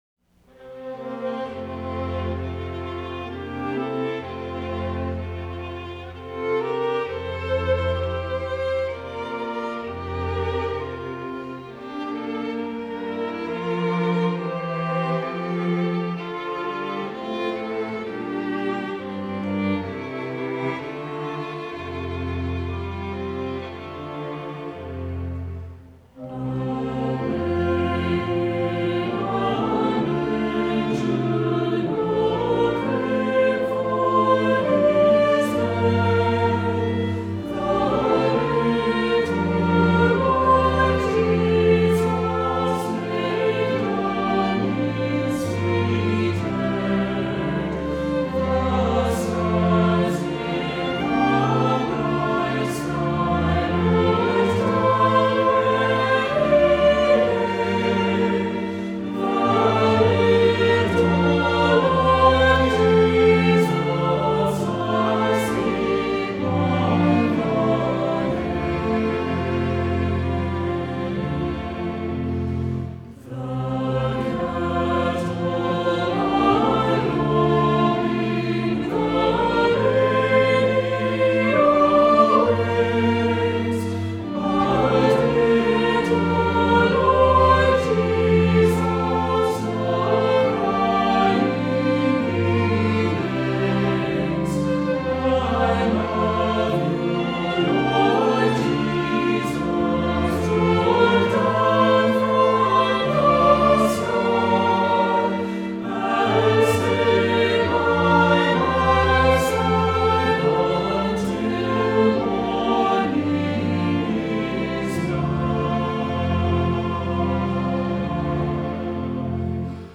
Voicing: Descant,Soprano Soloist,2-part Choir